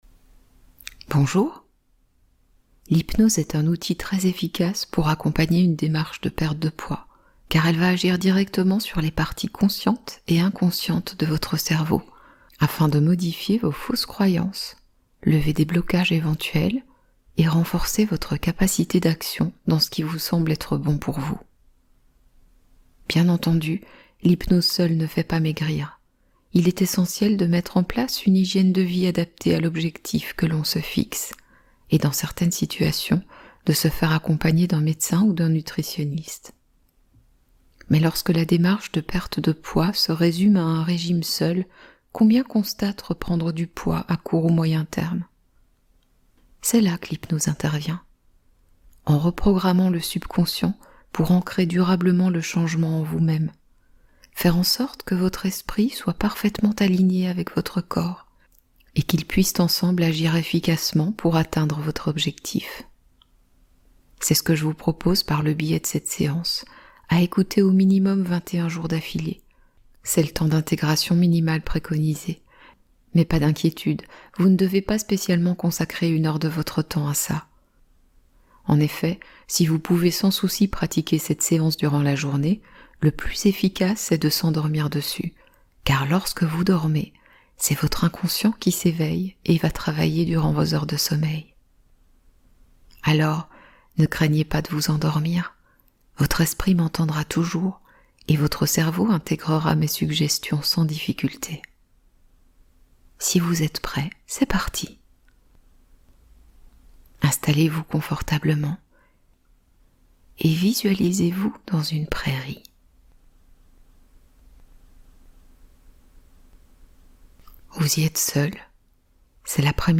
Maigrissez pendant que vous dormez | Hypnose sommeil profond reprogrammation minceur subconscient